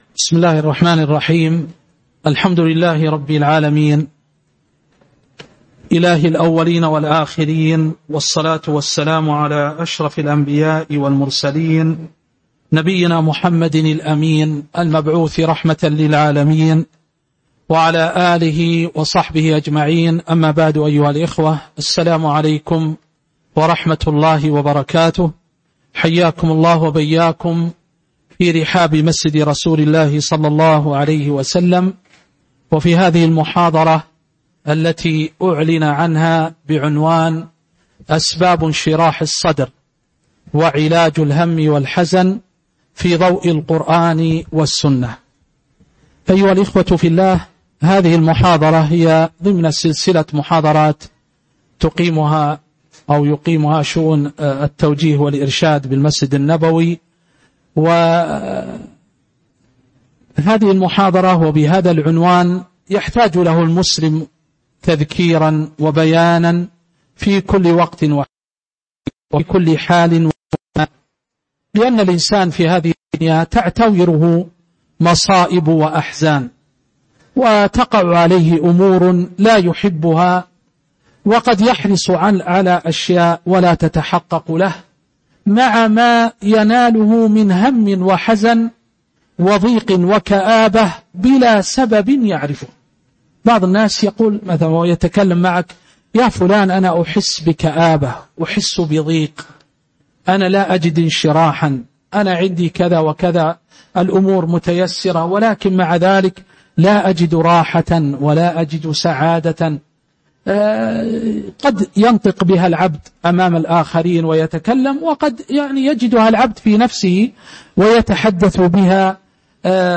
تاريخ النشر ٢١ رجب ١٤٤٤ هـ المكان: المسجد النبوي الشيخ